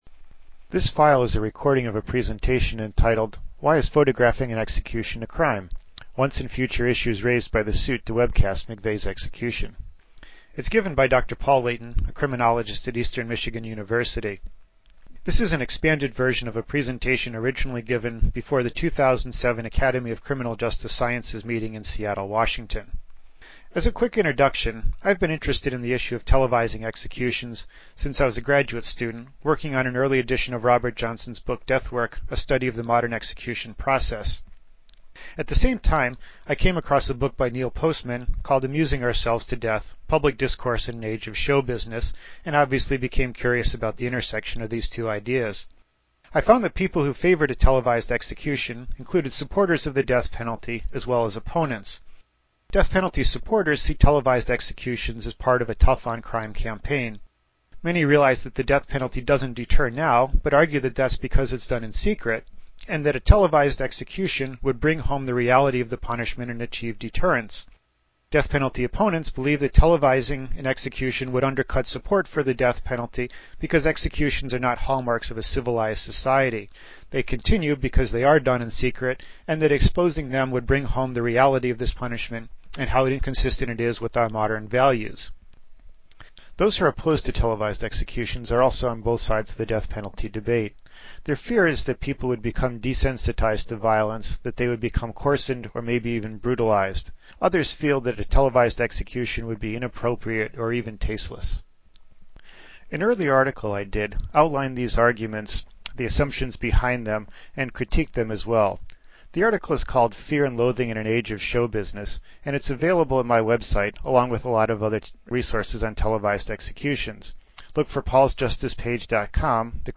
Earlier this month, I was out at the ACJS meeting in Seattle and presented some new research on televising executions. I made an mp3 recording of the presentation about webcasting McVeigh's execution and added a few minutes of background information about televised executions.